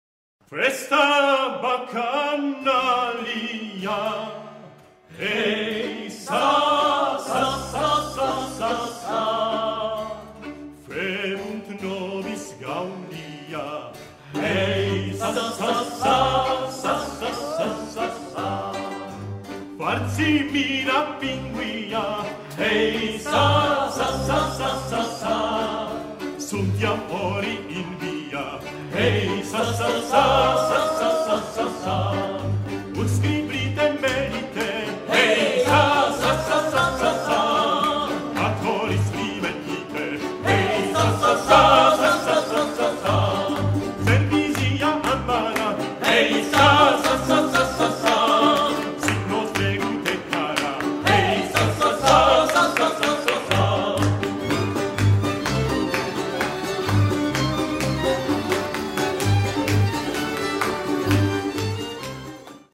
Drinking and feasting songs, chants and dances of Baroque Bohemia